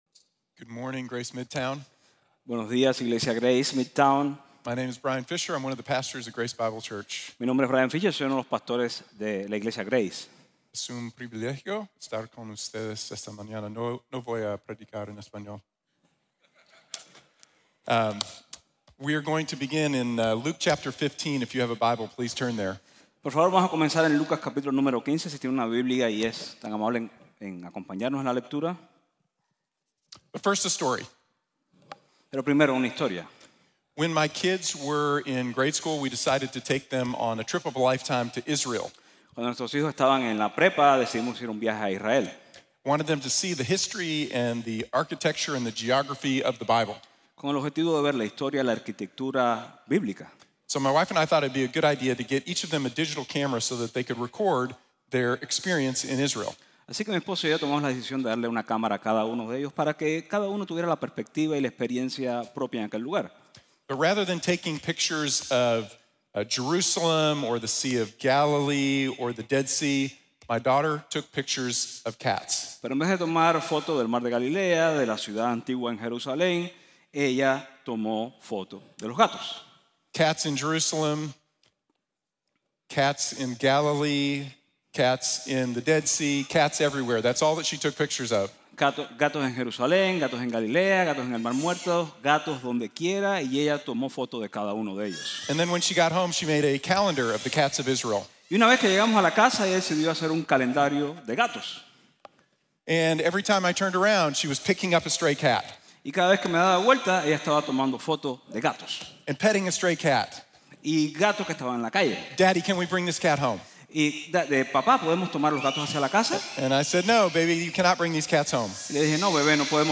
7 marcas de una iglesia floreciente | Sermón | Grace Bible Church